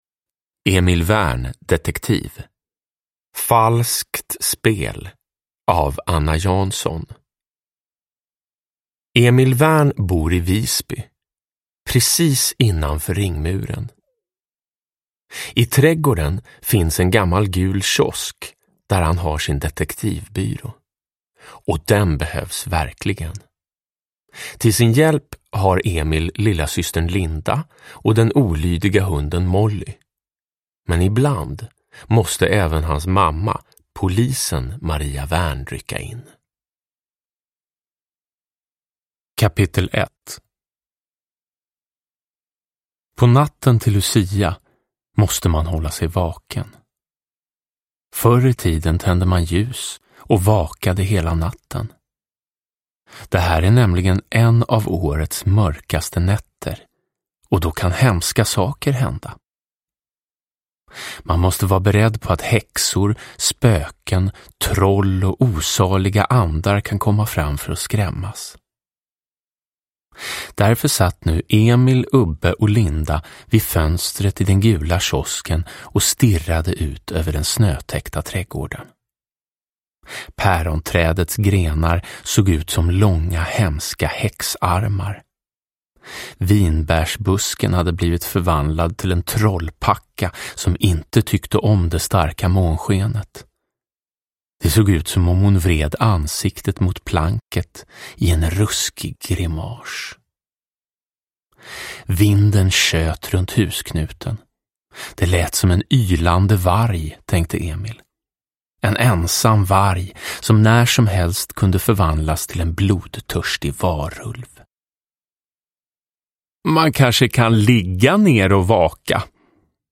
Falskt spel – Ljudbok – Laddas ner
Uppläsare: Jonas Karlsson